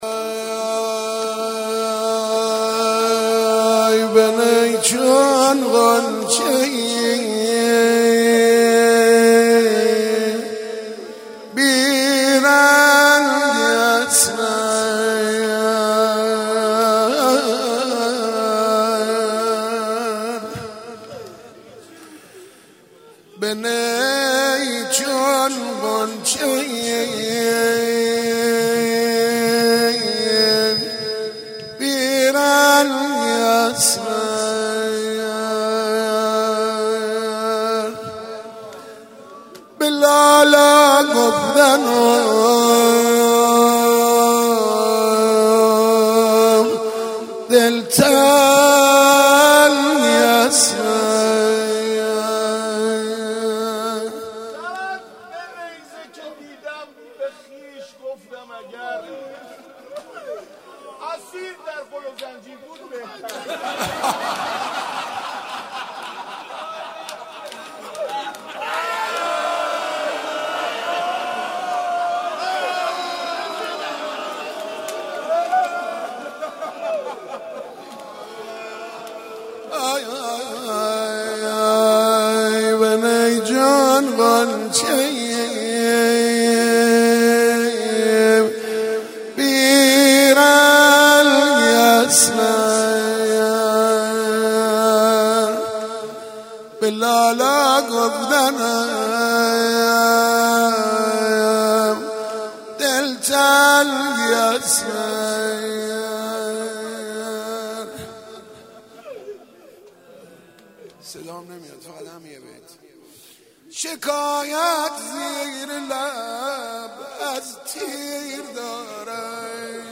مداح
قالب : روضه